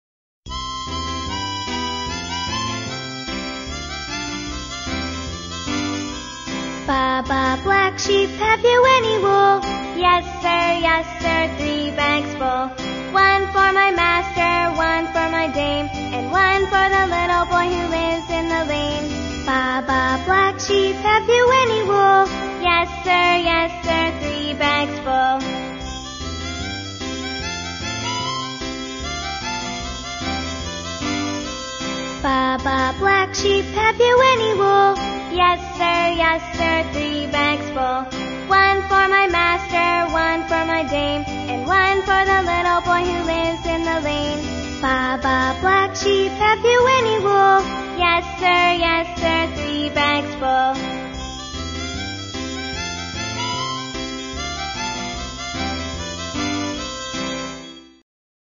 在线英语听力室英语儿歌274首 第13期:Baa Baa black sheep的听力文件下载,收录了274首发音地道纯正，音乐节奏活泼动人的英文儿歌，从小培养对英语的爱好，为以后萌娃学习更多的英语知识，打下坚实的基础。